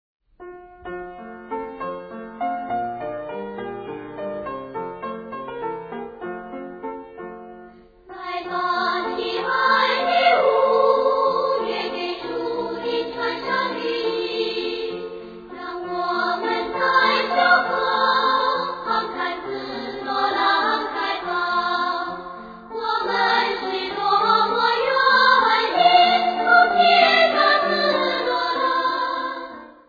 This CD collects 18 children songs from Italy
performed by one of the best Chinese choirs in China